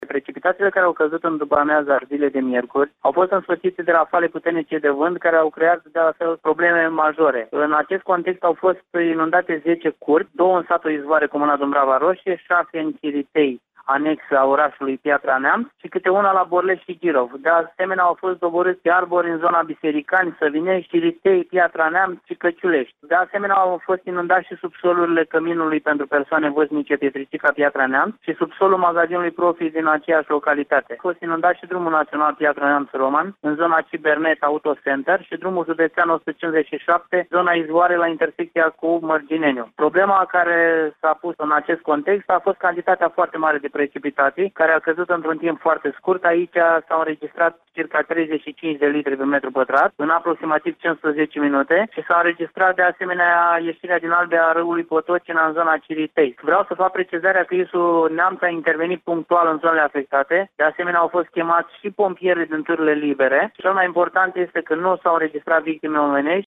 Prefectul de Neamţ, Vasile Panaite: